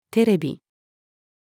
テレビ-female.mp3